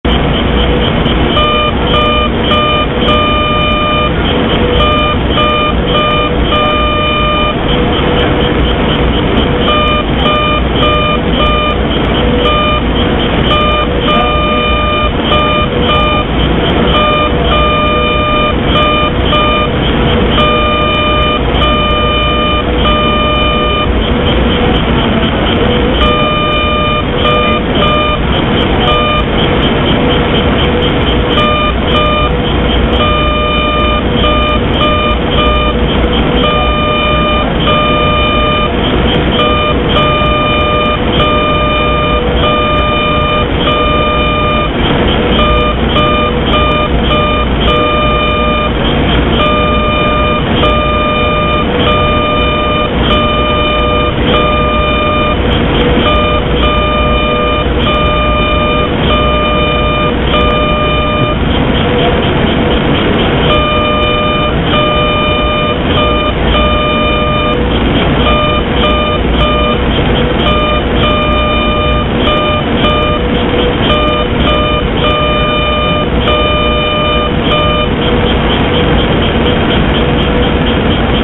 The demo involved the IBM 1401 computer sending a greeting in Morse Code, by manipulating the RFI produced by its operation.
Where? Mountain View California.
Those glitches will produce an audible tone.